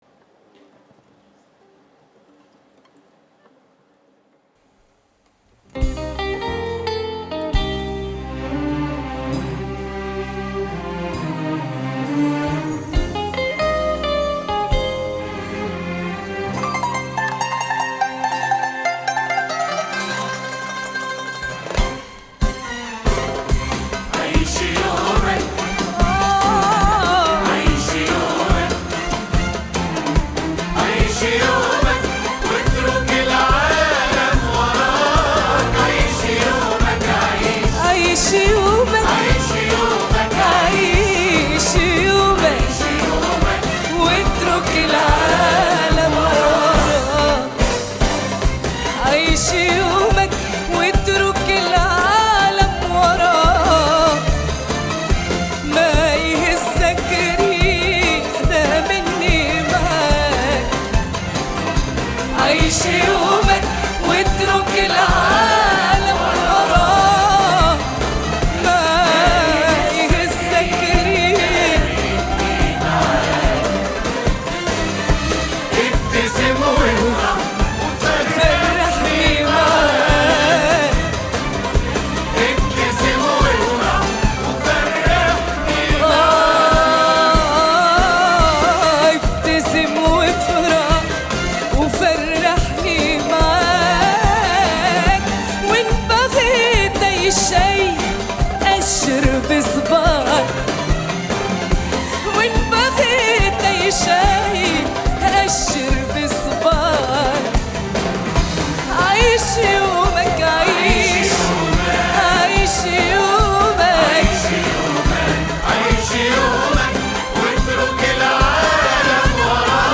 دار الأوبرا 2025